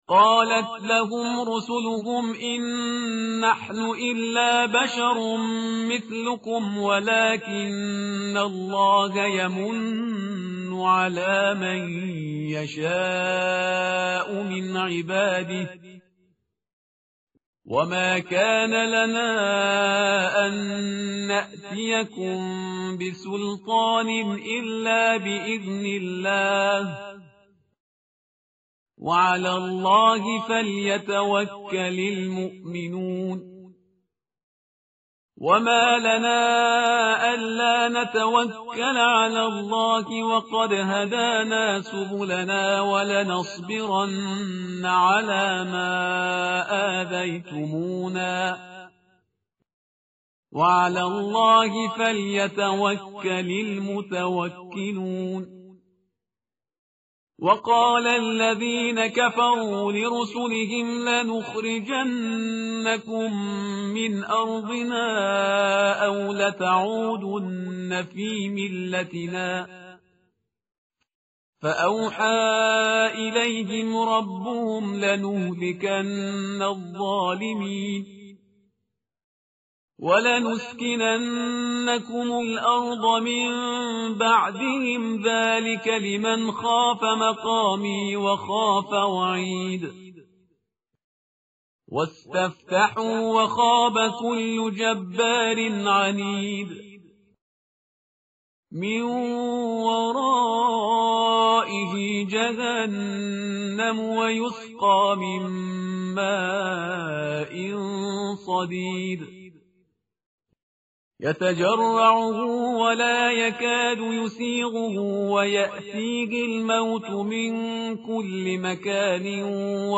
tartil_parhizgar_page_257.mp3